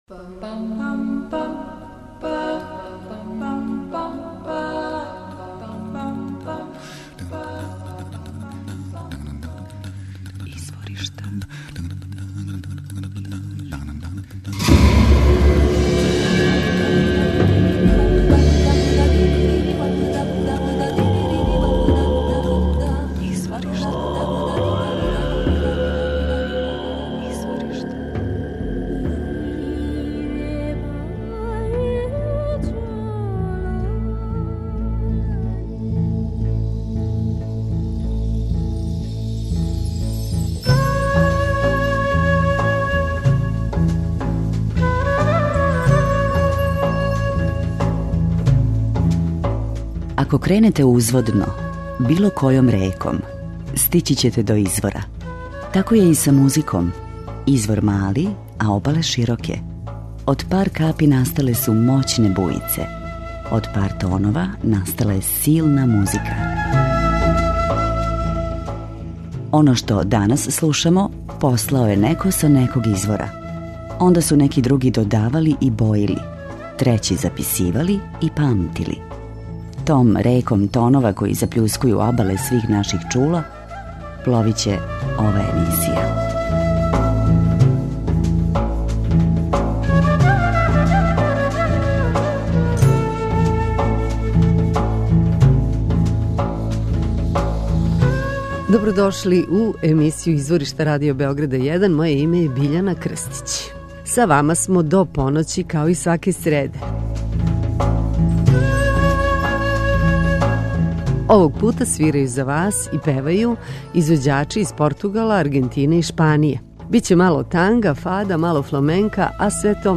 нео танго
хип хоп са мало фламенка
комбинацију танга са електроником
У две речи: World Music.